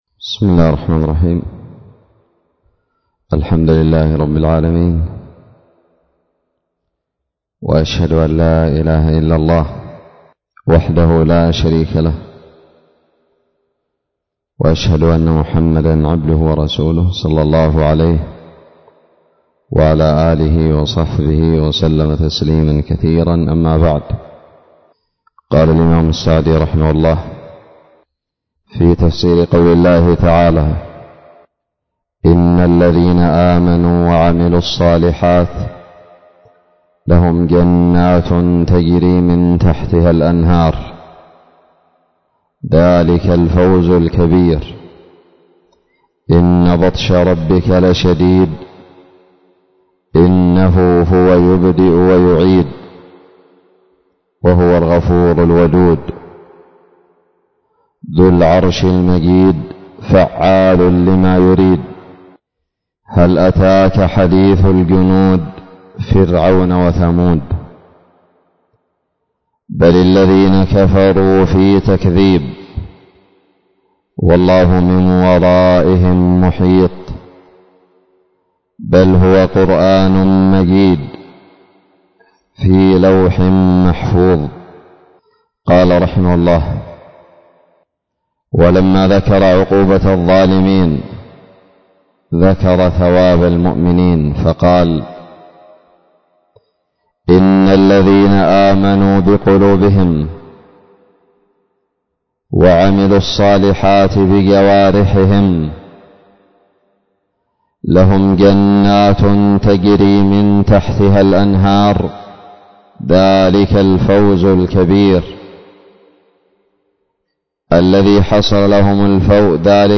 الدرس الثاني والأخير من تفسير سورة البروج
ألقيت بدار الحديث السلفية للعلوم الشرعية بالضالع